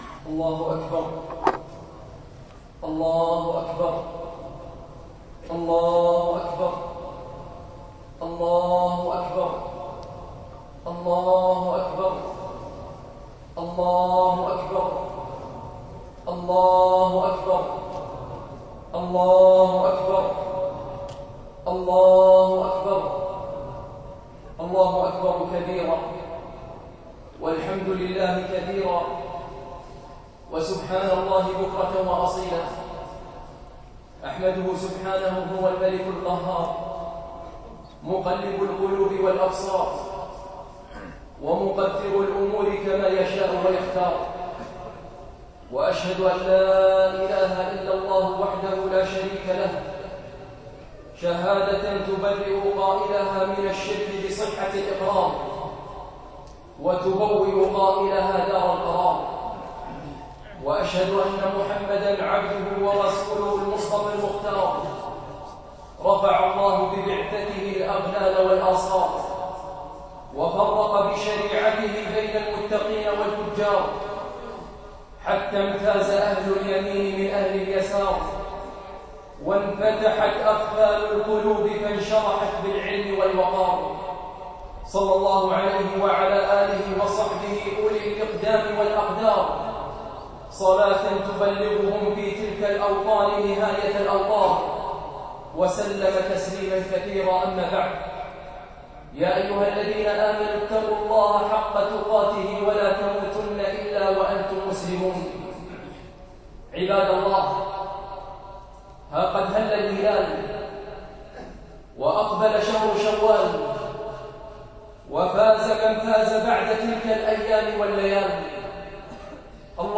في مسجد العلاء بن عقبة مسجد جمعية الفردوس
خطبة عيد الفطر ١٤٣٧